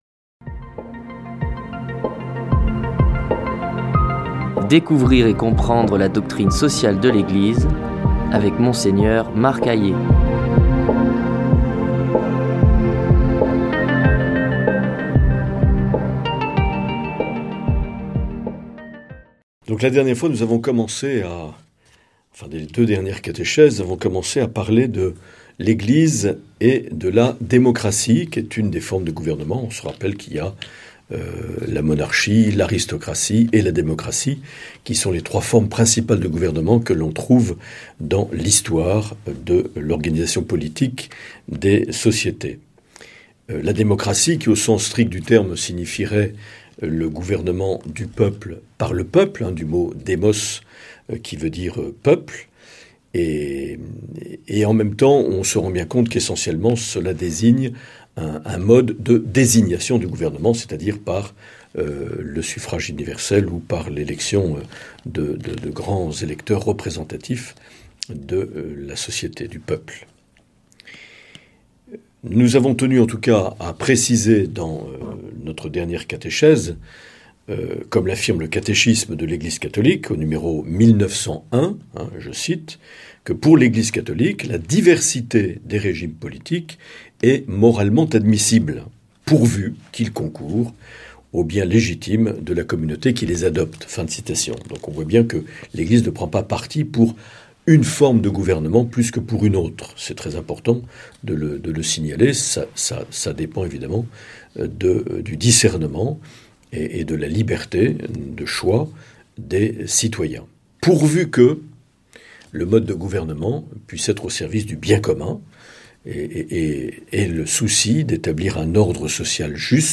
Présentateur(trice)